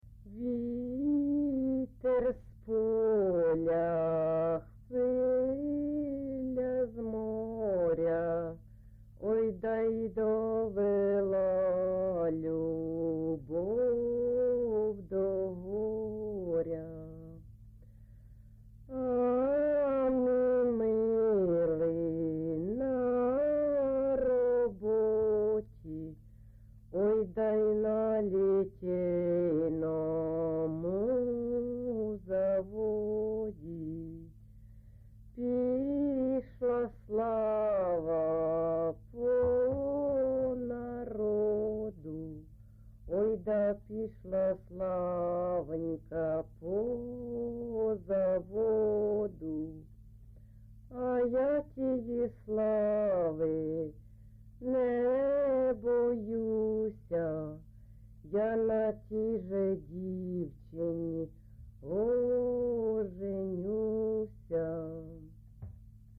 ЖанрПісні з особистого та родинного життя
Місце записус. Оленівка Волноваський район, Донецька обл., Україна, Слобожанщина